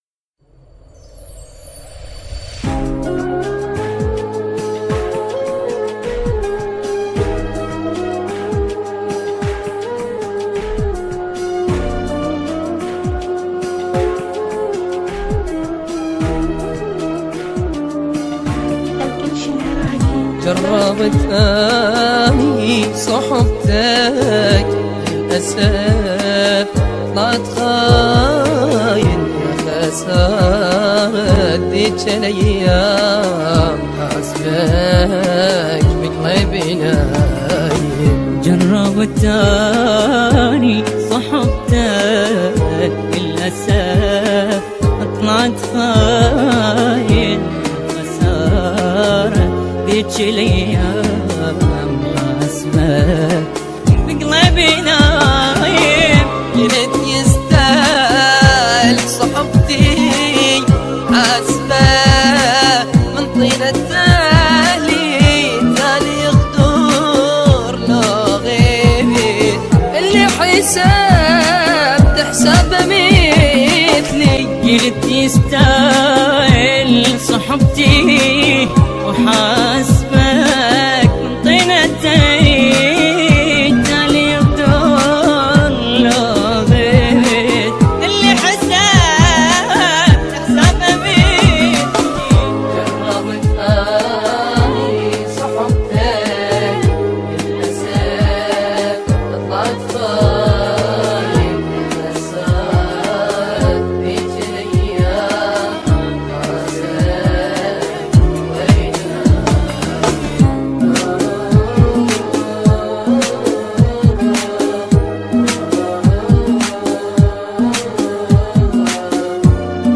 اغاني عراقيه